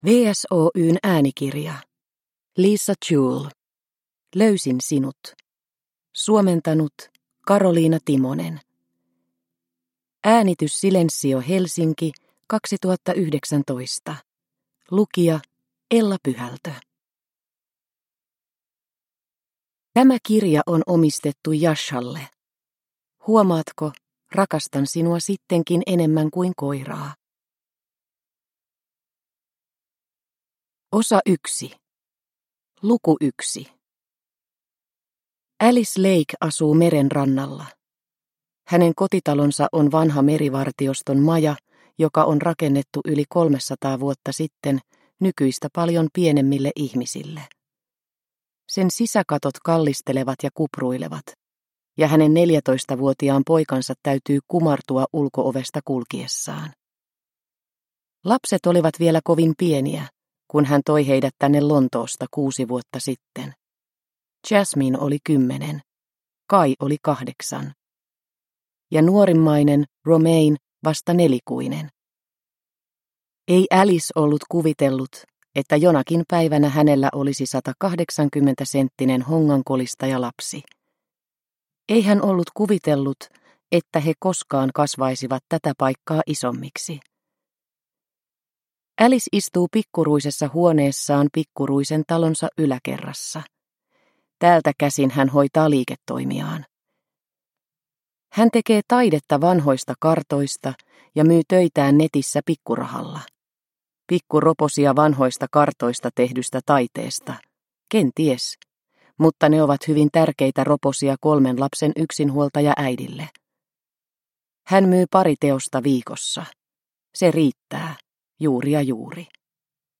Löysin sinut – Ljudbok – Laddas ner